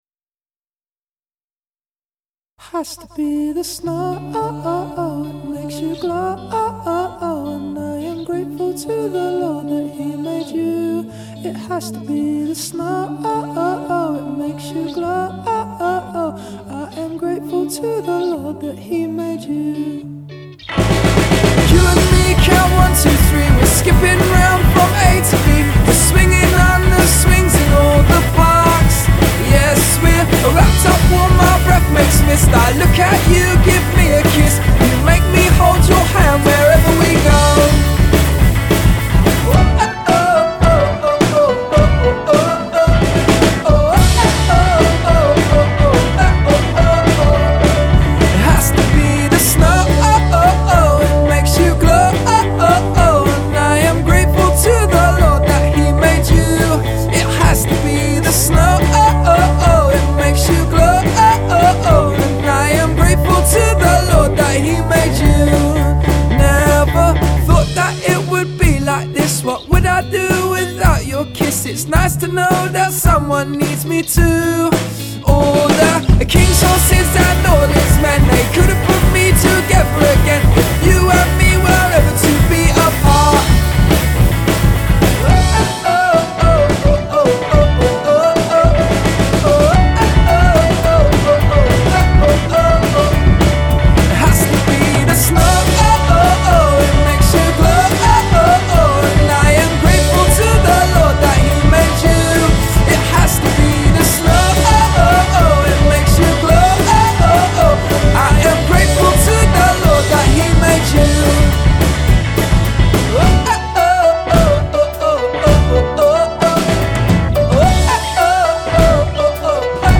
Cheeky voice? Check. Catchy chorus? A-yup.